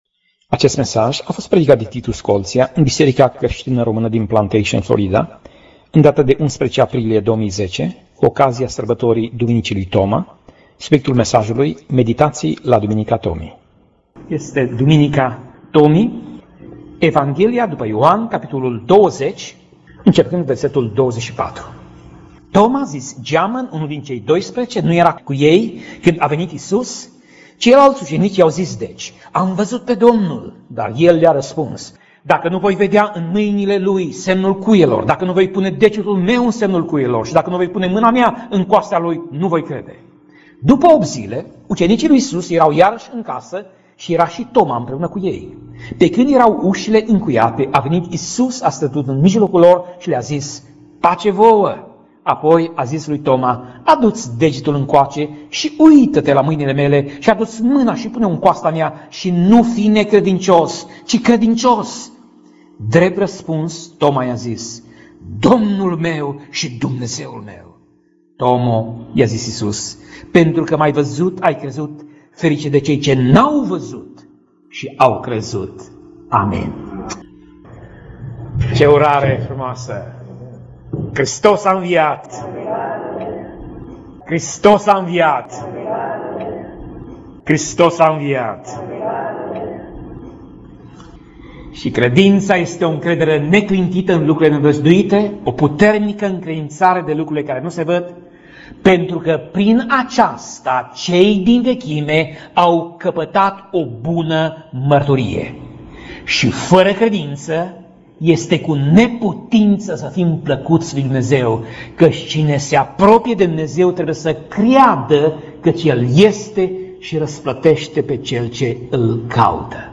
Pasaj Biblie: Ioan 20:24 - Ioan 20:29 Tip Mesaj: Predica